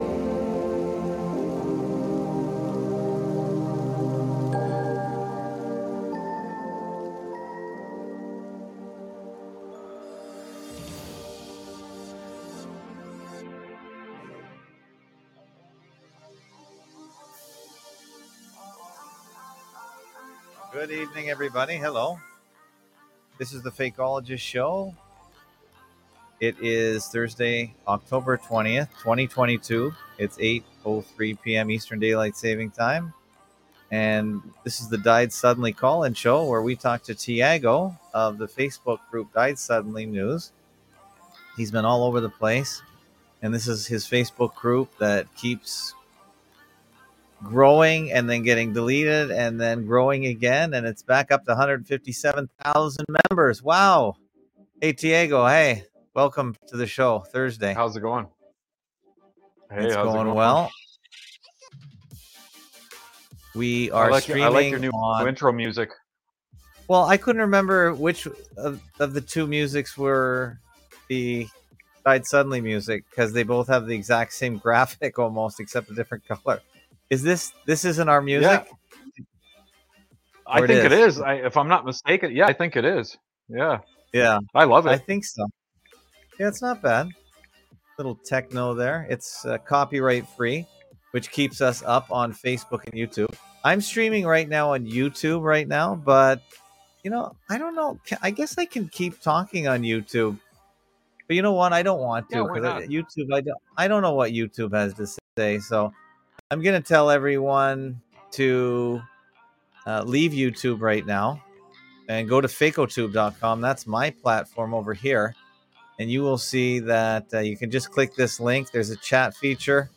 FAK635-Died Suddenly Call in Show
Fakeologist show Live Sun-Thu 830pm-900pm EDT